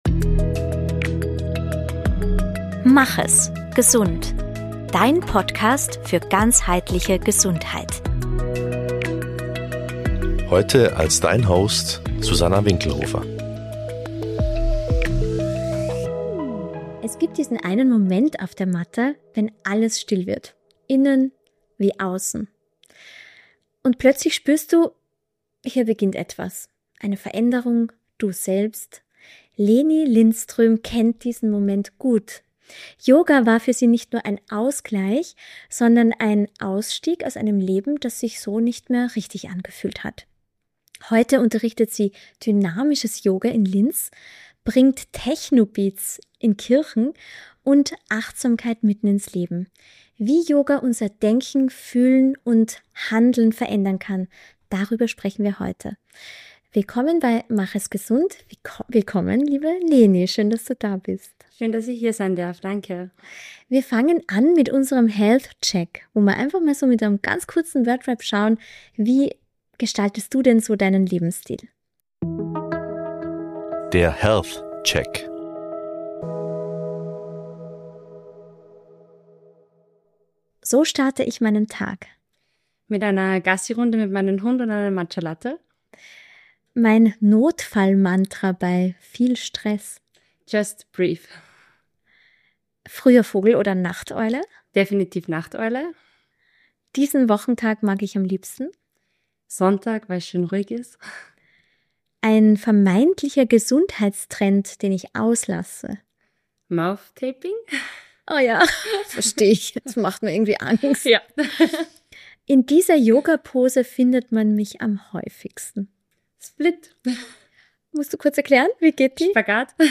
Ein Gespräch über Transformation, über den Mut zum Neubeginn – und darüber, wie viel Kraft darin liegt, wenn wir das Ego auf der Matte ablegen.